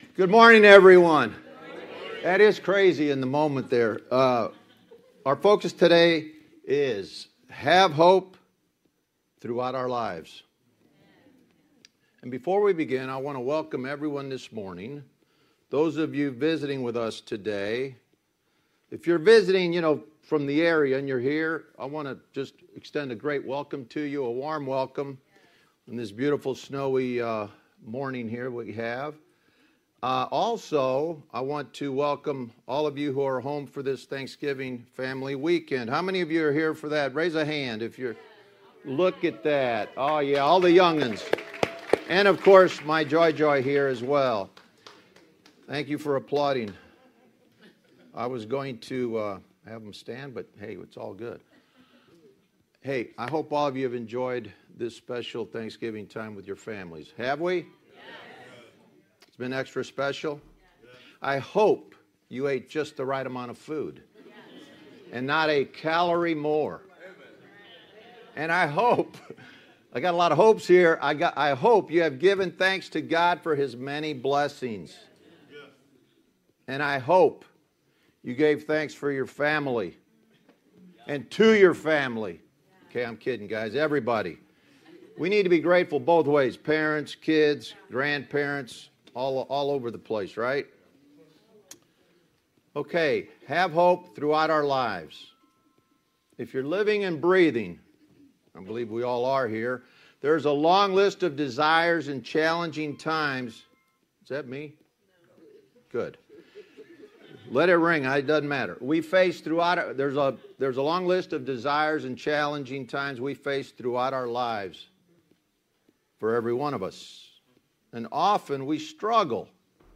Sermons | Gateway City Church
Guest Speaker